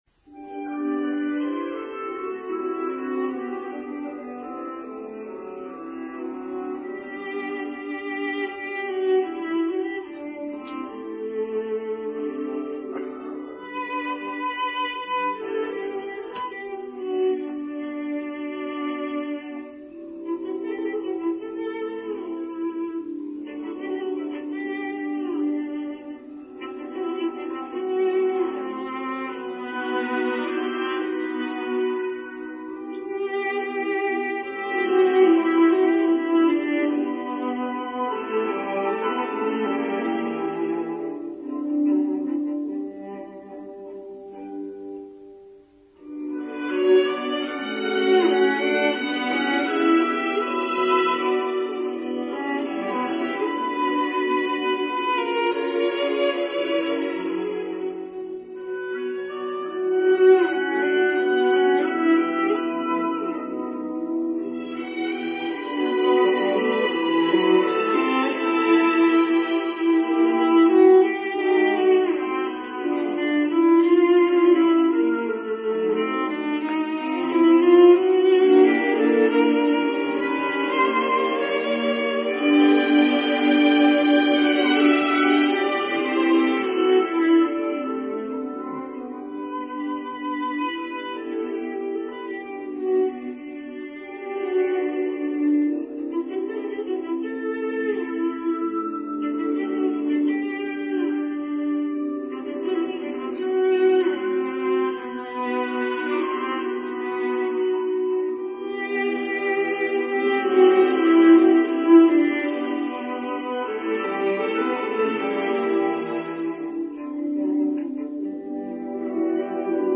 Prelude Chanson de Matin by Edward Elgar